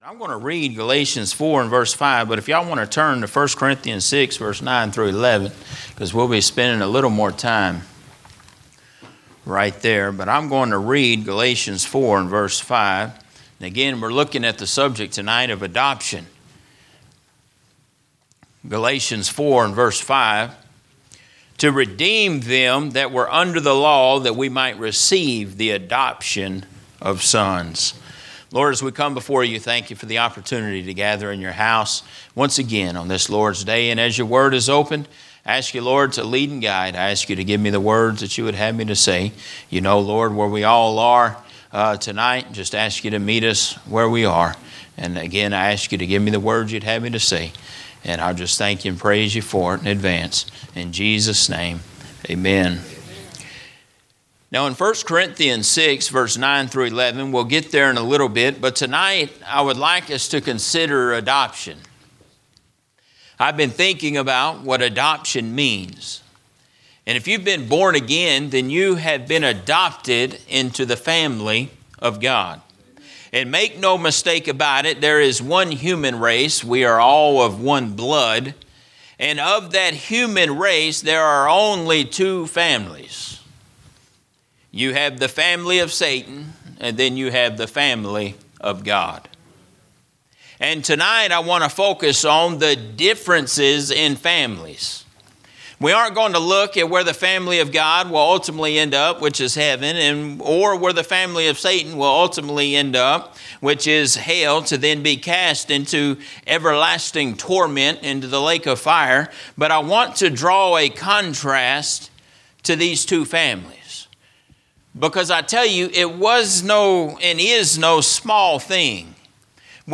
A message from the series "General Preaching."
From Series: "General Preaching"